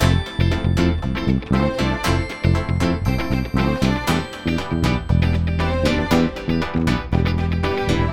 28 Backing PT3.wav